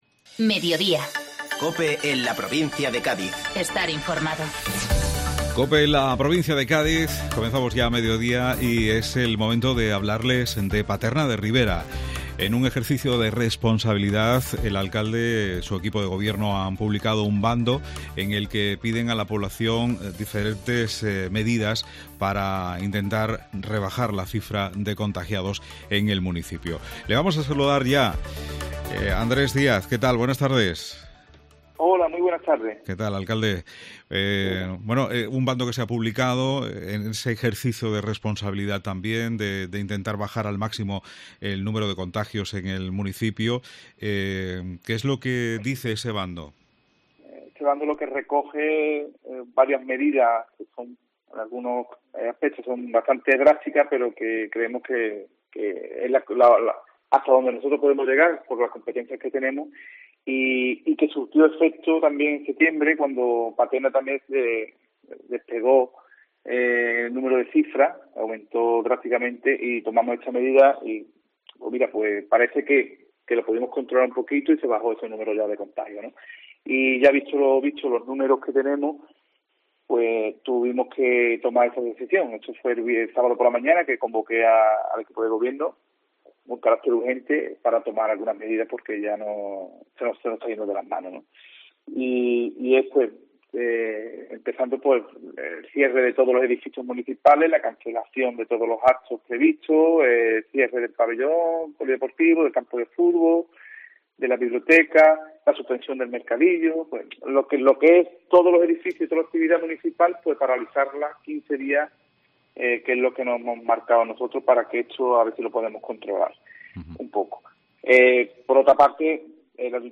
Andrés Díaz, Alcalde de Paterna de Rivera